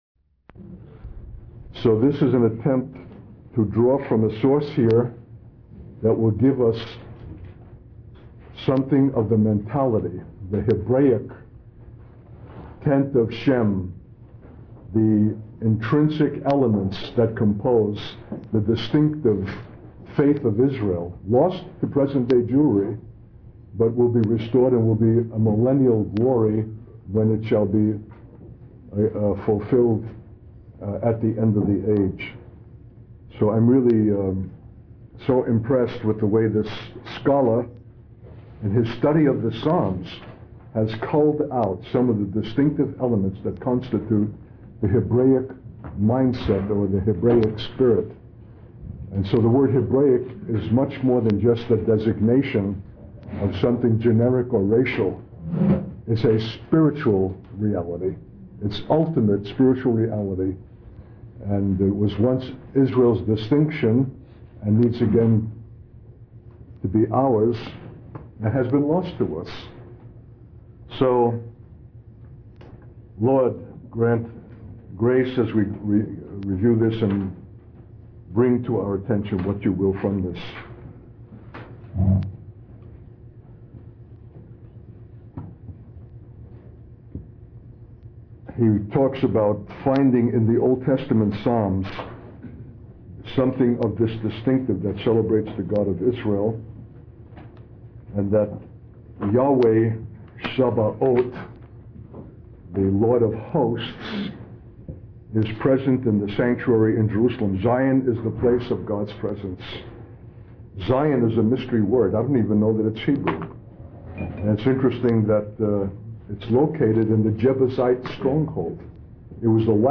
In this sermon, the speaker emphasizes the importance of the presence of God in the worship of Israel.